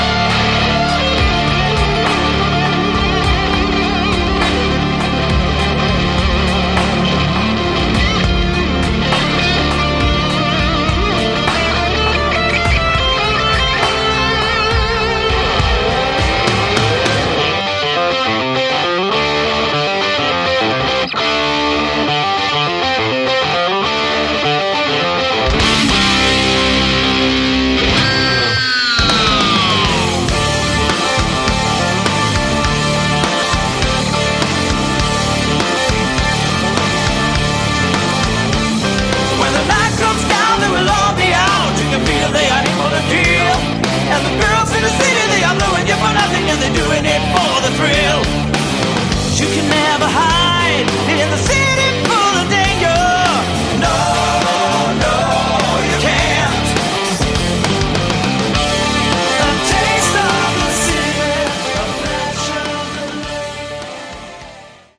Category: Melodic Hard Rock/Metal
Vocals
Guitars, Keyboards
Bass
Drums